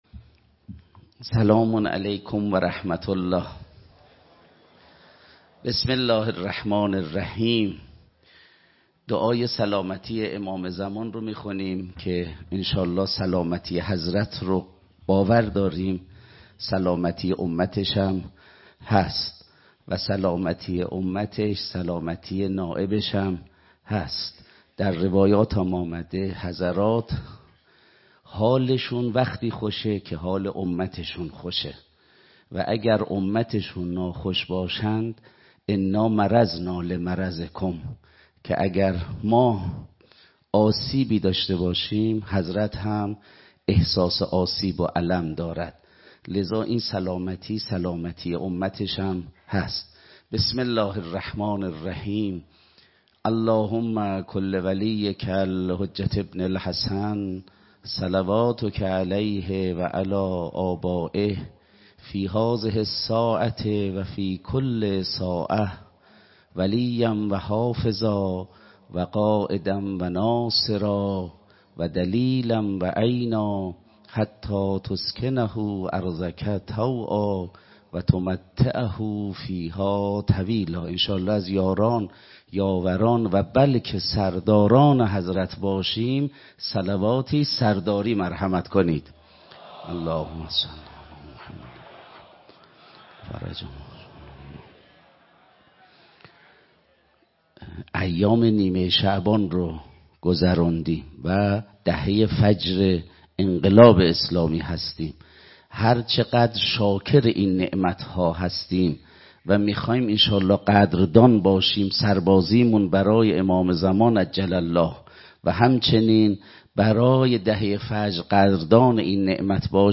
سخنرانی
در سلسله نشست های طلیعه رمضان 1447ه.ق